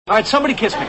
These are .mp3 soundbites from the NBC television show "Friends."